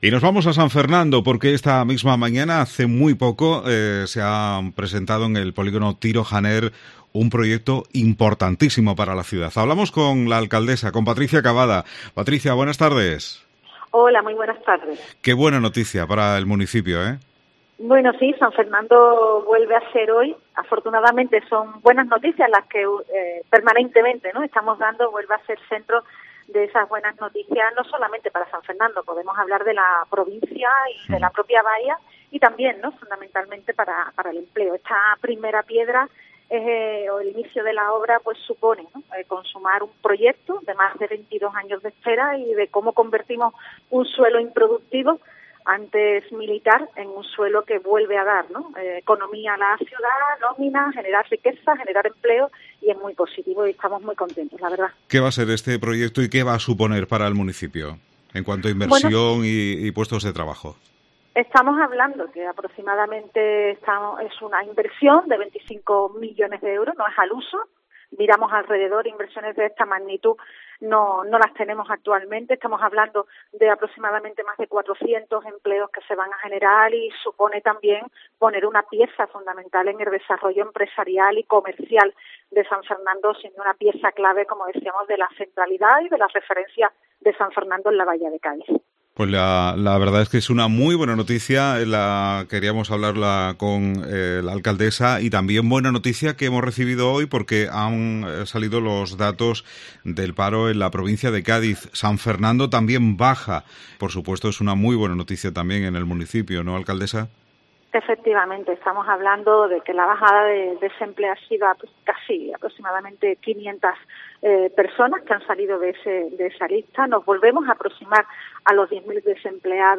Patricia Cavada, Alcaldesa de San Fernando primera piedra del Polígono Tiro Janer